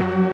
Freq-lead32.ogg